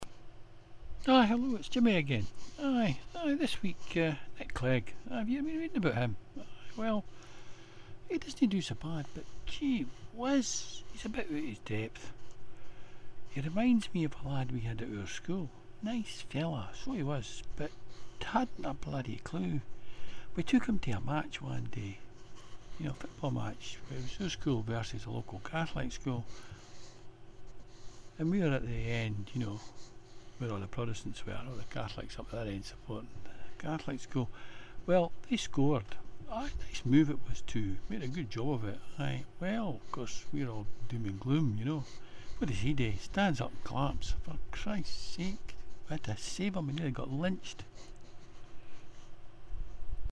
An irreverant rant at the celebrities and politicians that inhabit the UK News from a bad tempered Scotsman.